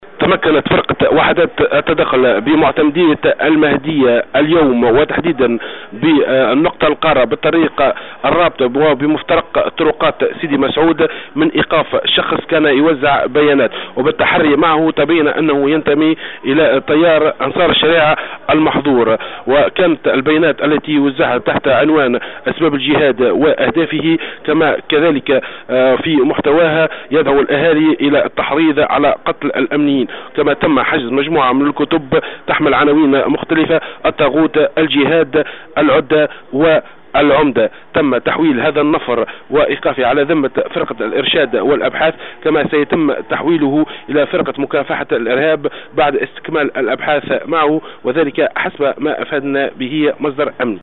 مراسلنا في المهدية